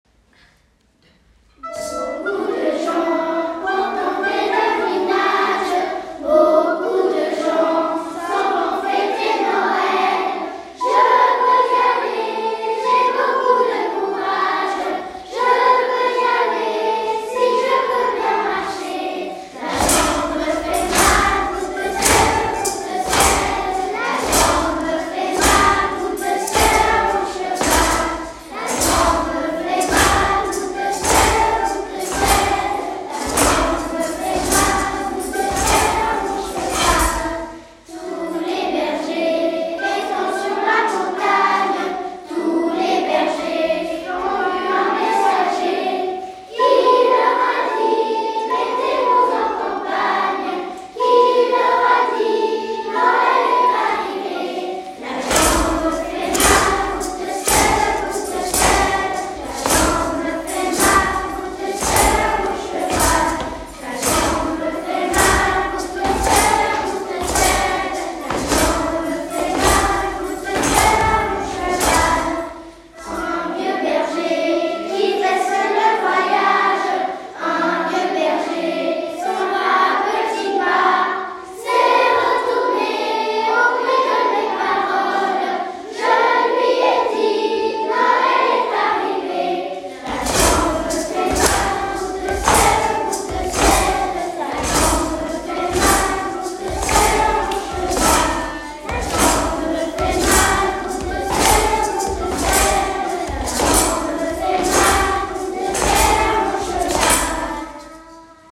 2021-22 : “Chantée de Noël”, les classes de Corcelles
Groupe 2 : classes 1-2P43, 6P42 et 4P42